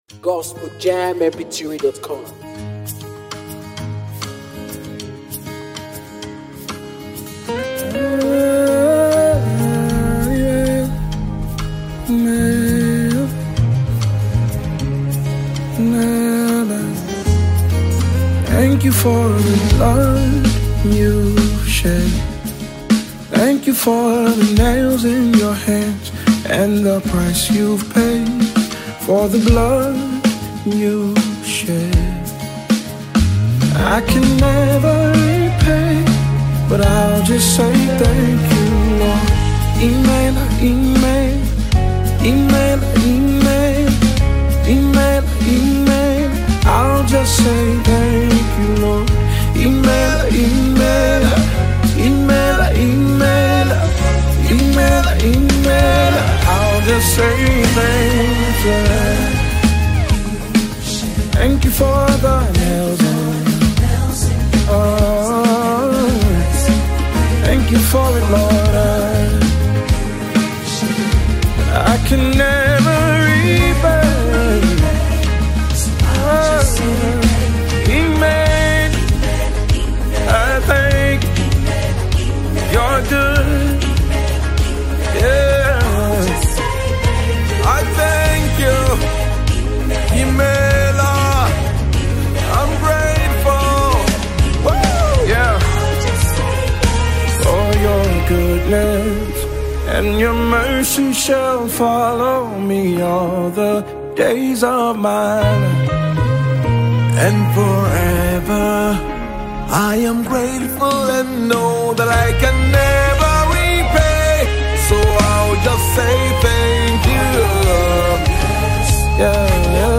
this song was recorded live